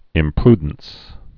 (ĭm-prdns)